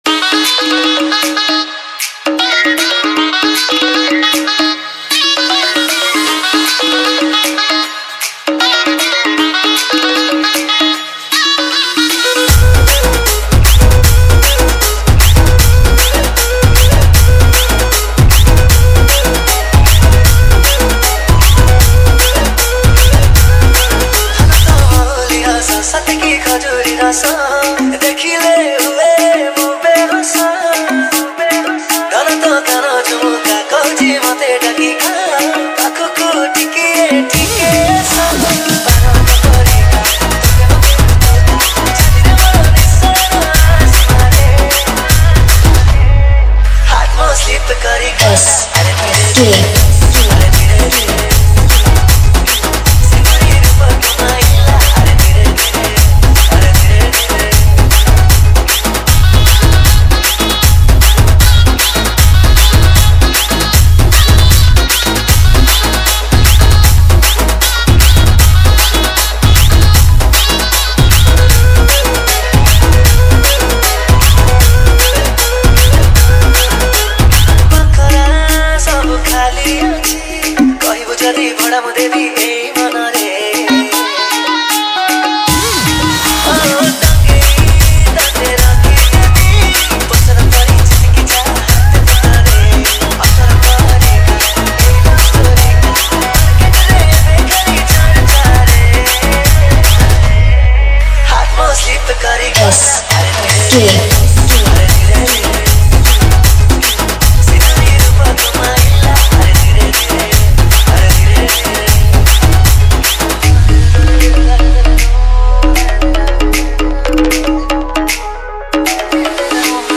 ODIA ROMANTIC DJ REMIX